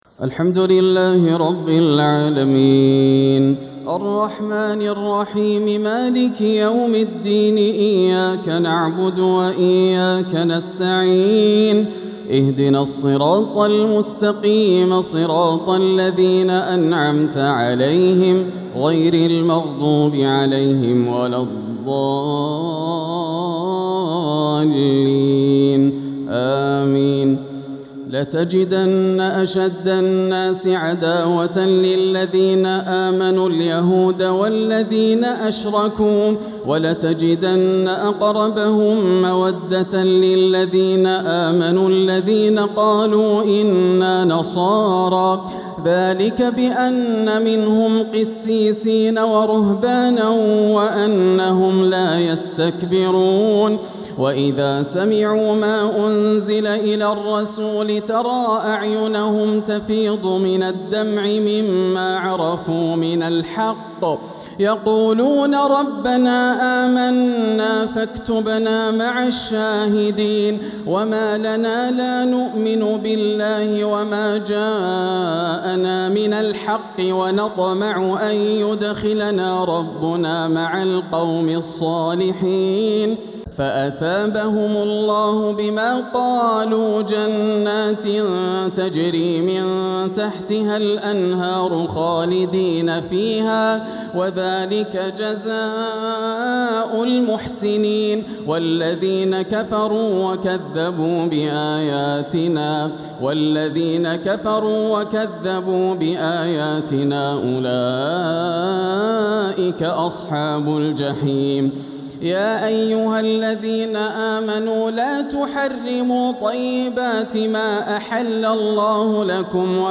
تراويح ليلة 9 رمضان 1447هـ من سورتي المائدة {82-120} و الأنعام {1-20} | Taraweeh 9th night Ramadan 1447H Surat Al-Ma'idah and Al-Ana'am > تراويح الحرم المكي عام 1447 🕋 > التراويح - تلاوات الحرمين